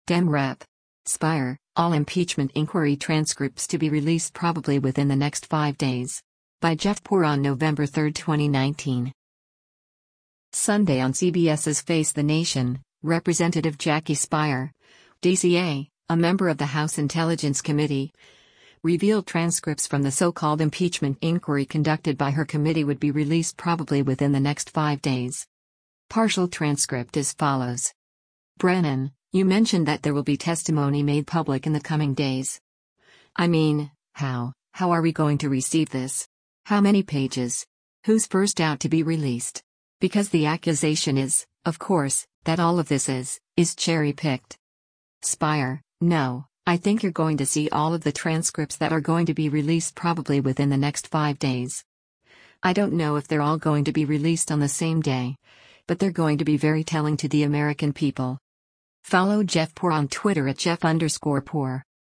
Sunday on CBS’s “Face the Nation,” Rep. Jackie Speier (D-CA), a member of the House Intelligence Committee, revealed transcripts from the so-called impeachment inquiry conducted by her committee would be released “probably within the next five days.”